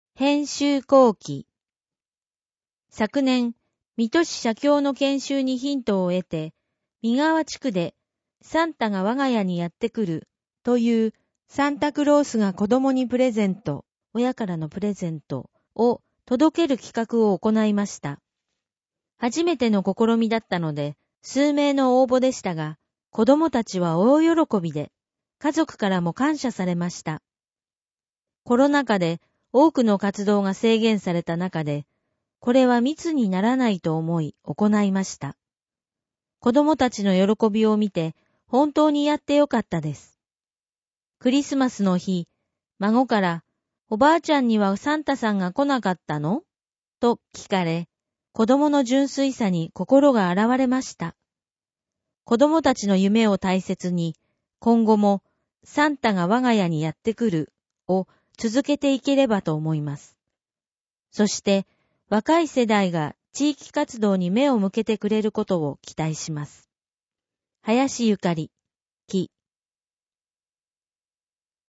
音声ガイド
音声ガイドでお聞きいただけます。（音声データ作成：音訳ボランティア「こだま」）